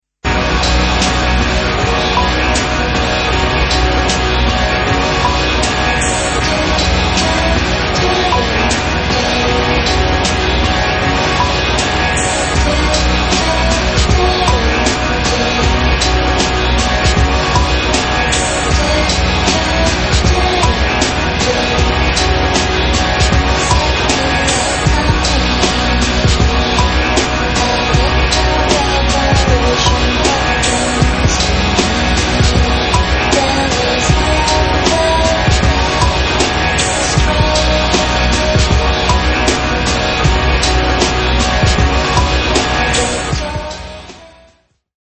Guitar
harmonic slow voc.